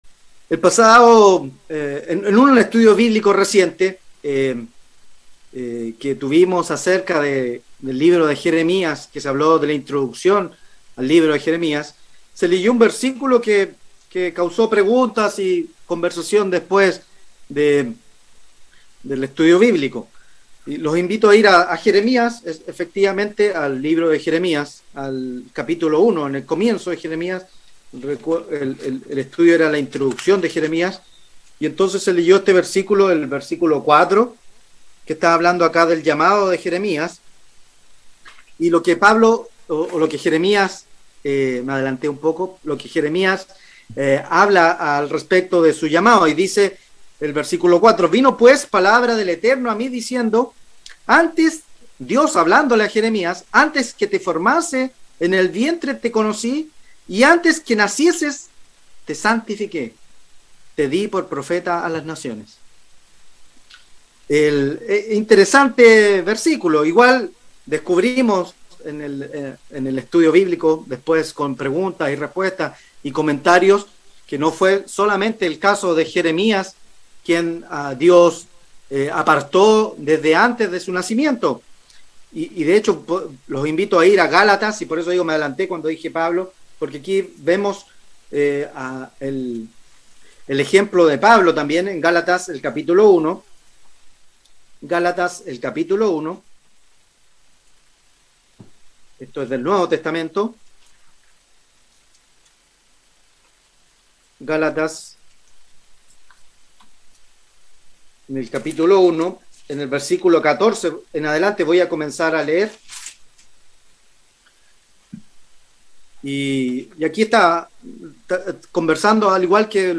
Mensaje entregado el 9 de octubre de 2021.